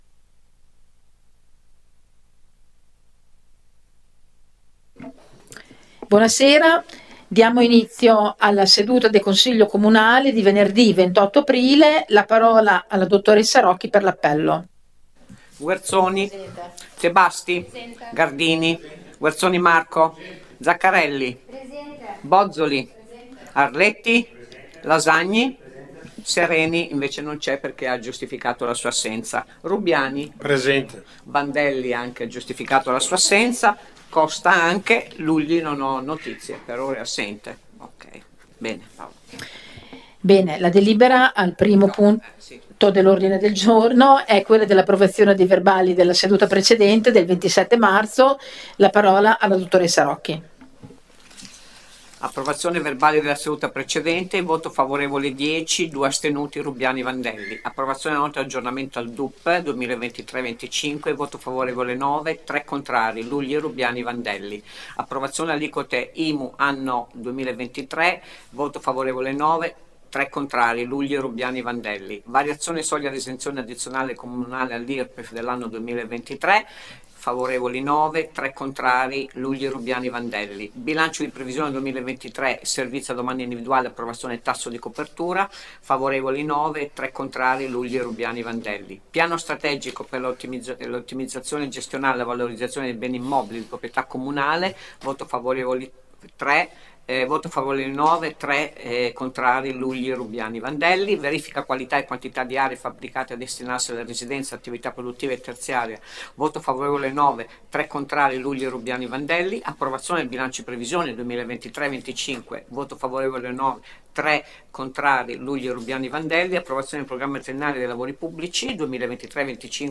Accedendo a questa pagina è possibile ascoltare la registrazione della seduta del Consiglio comunale.